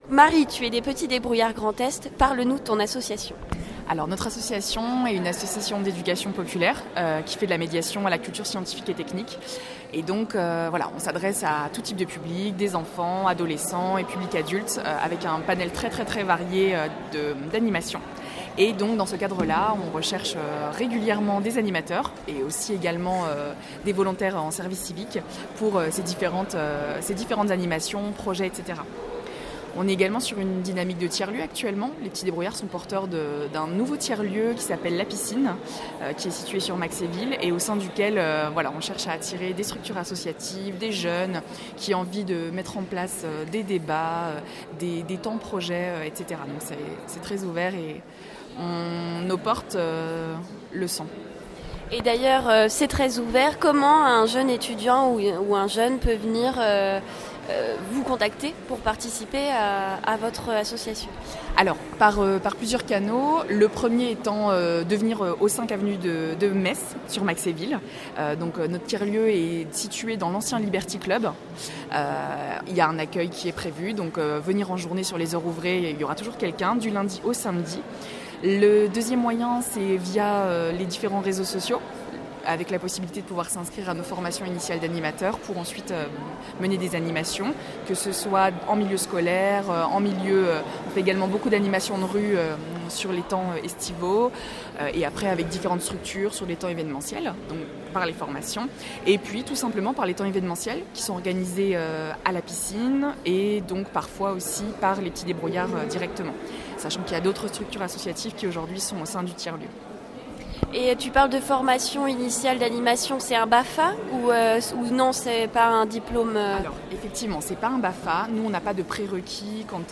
Les officiels de la vie étudiantes (transports, logements, restauration, sécu, job, etc.) et quelques associations nancéiennes se sont réunies pour un salon à l’Hôtel de ville de Nancy le samedi 7 juillet 2018.